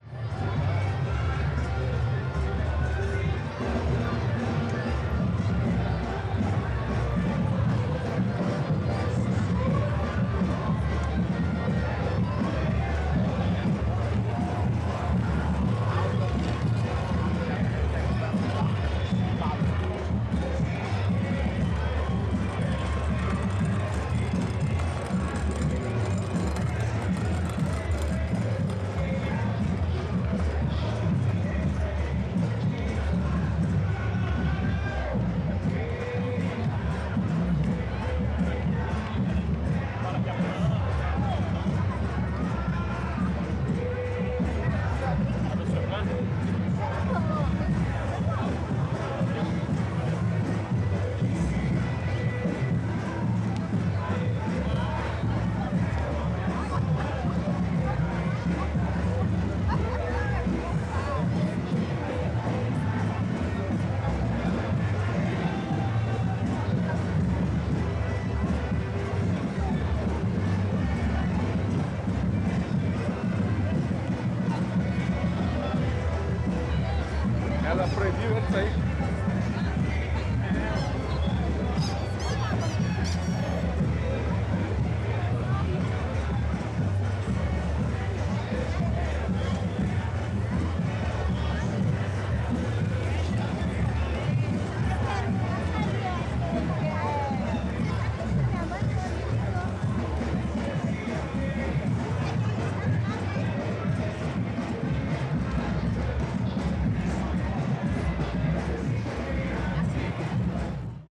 CSC-04-355-GV - Ambiencia no Eixao do Lazer com Batucada de Axe .wav